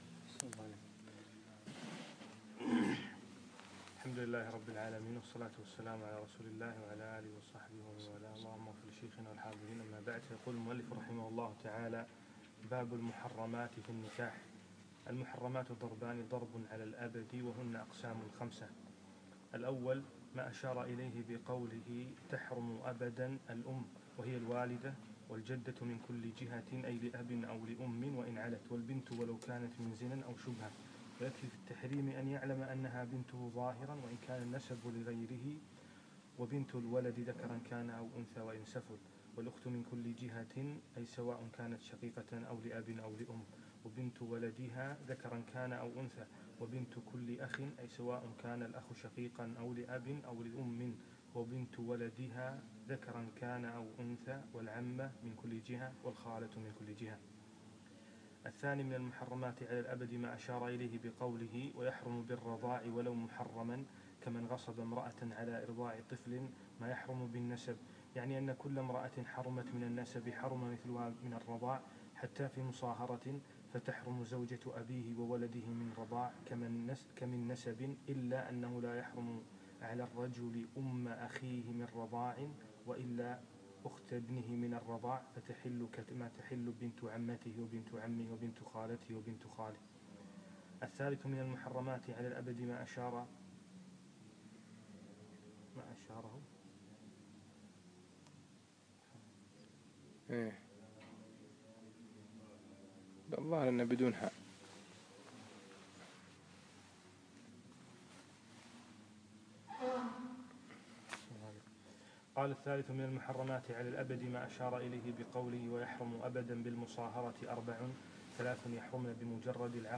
الدرس الحادي والثلاثون : باب المحرمات في النكاح